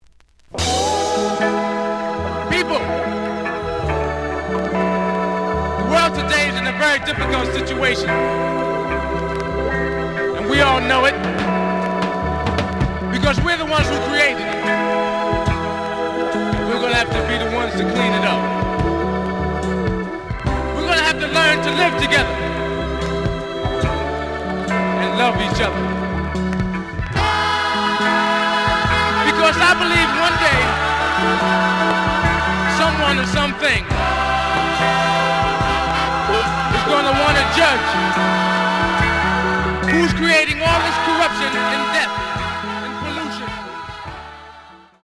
The audio sample is recorded from the actual item.
●Genre: Funk, 70's Funk
B side is slight cracking sound.)